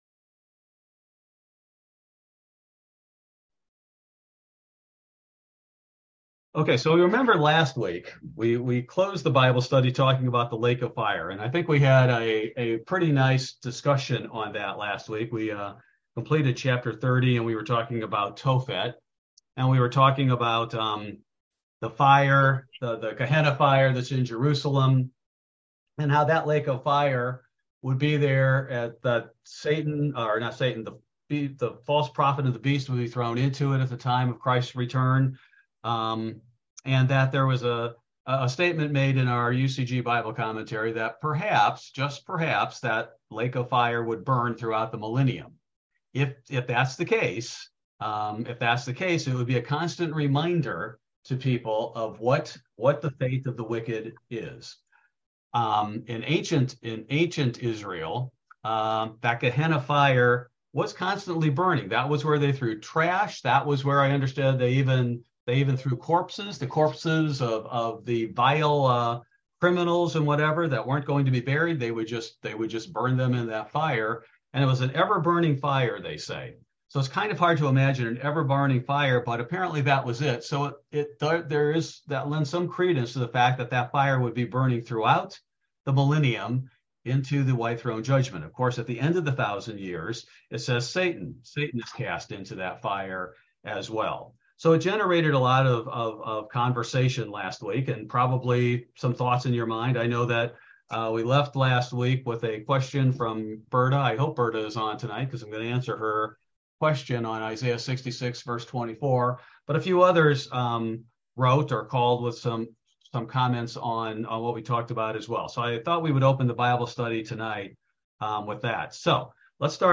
Bible Study: May 3, 2023